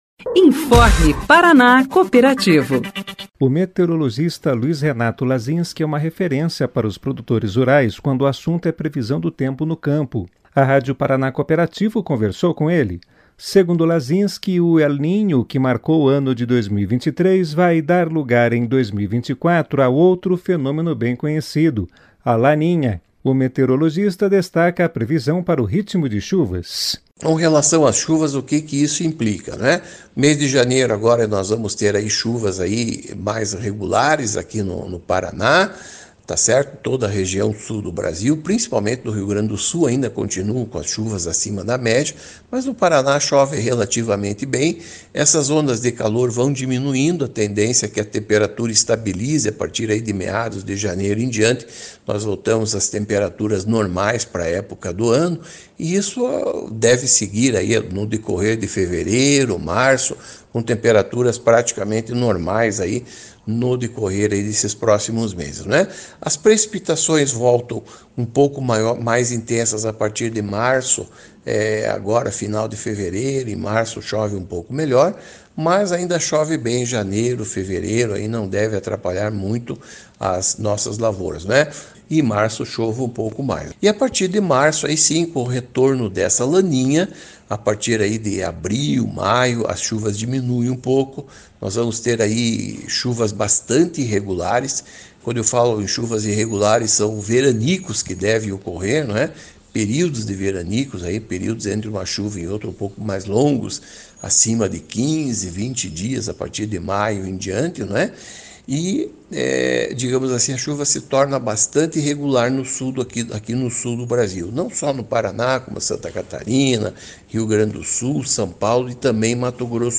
A Rádio Paraná Cooperativo conversou com ele.